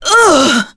Tanya-Vox_Damage_05.wav